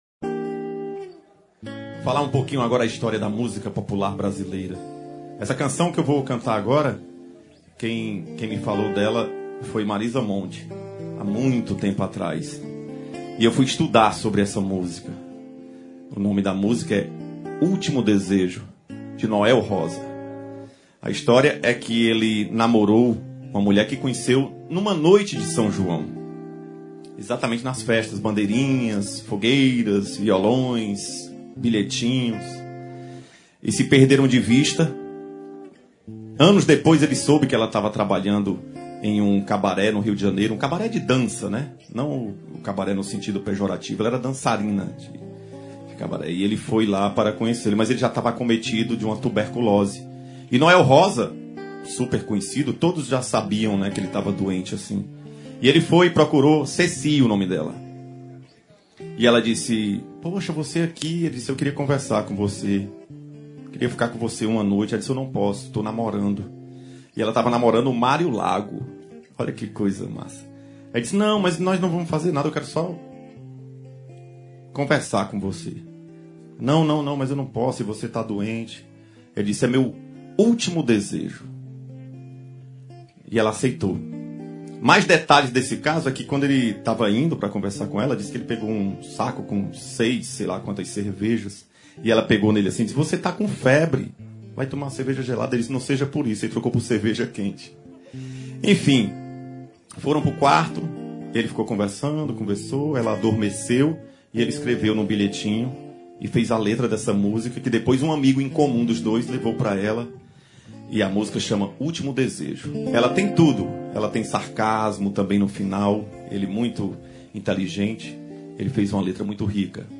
Sanfoneiro